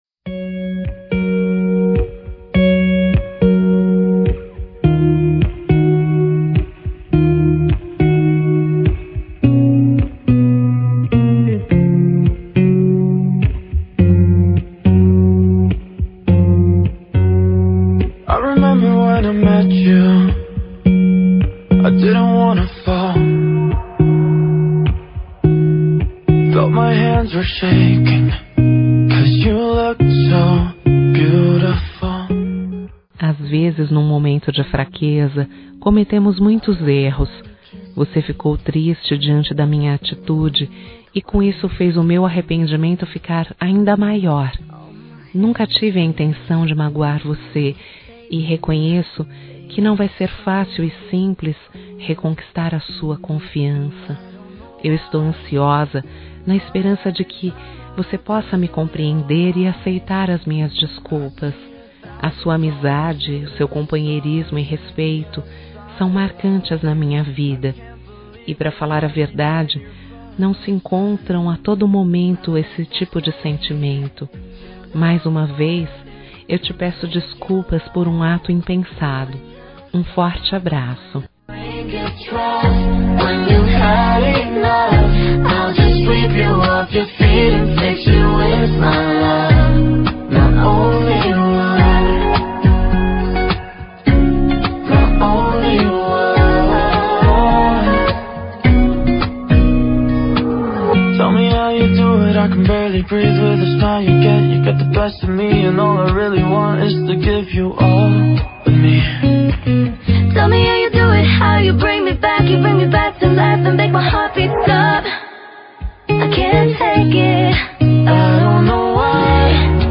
Telemensagem de Desculpas Geral – Voz Feminina – Cód: 5465